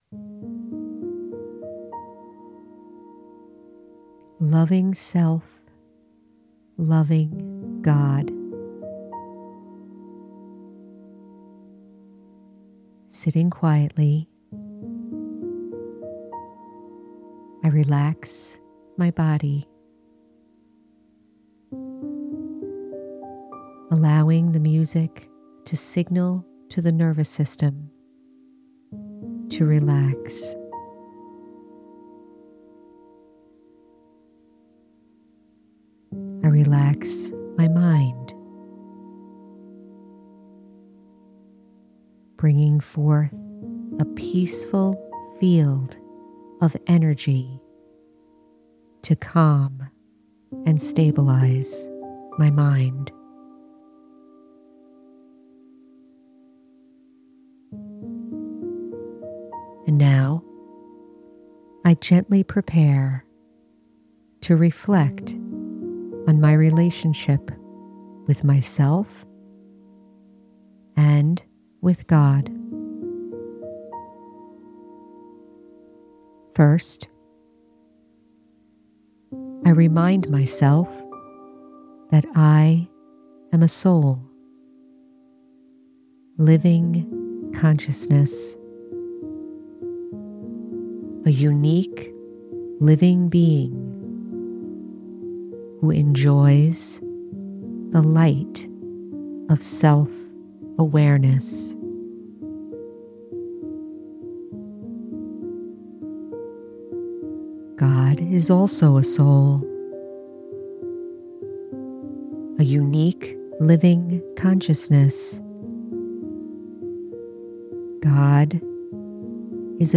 Loving Self Loving God- Guided Meditation- The Spiritual American- Episode 171